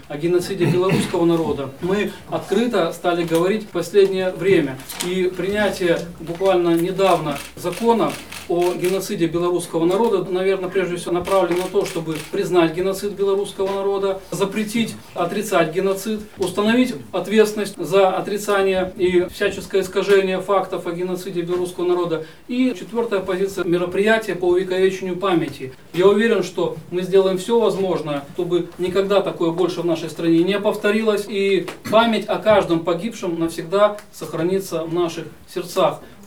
Мы вправе и должны дать справедливую оценку злодеяниям нацистов и их пособников на белорусской земле в годы Великой Отечественной и в послевоенный период, сказал парламентарий Игорь Хлобукин во время открытия выставочной экспозиции «Забвению не подлежит» в Барановичском краеведческом музее. Не так давно Палата представителей Национального собрания приняла законопроект «О геноциде белорусского народа», который устанавливает уголовную ответственность за публичное отрицание геноцида белорусского народа, отметил Игорь Хлобукин.